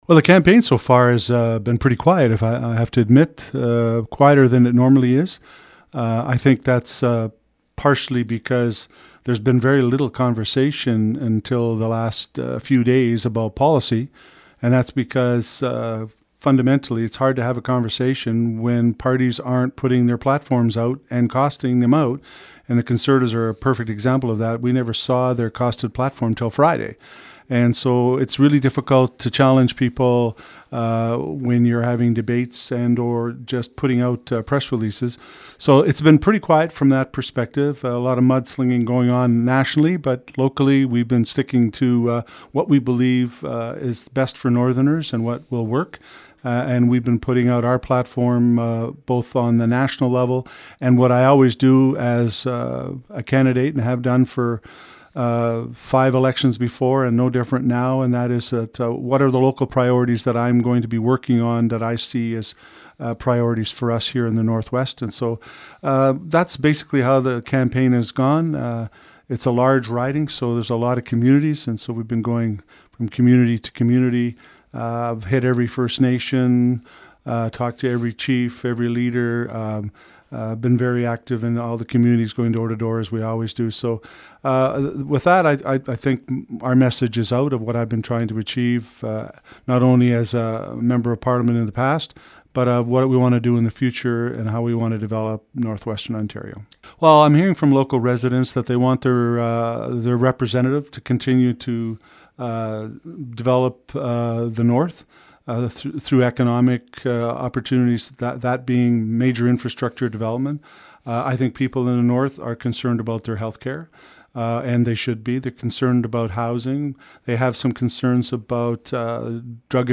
Liberal Bob Nault is seeking re-election and he took time this past week to speak with CKDR News about a number of issues.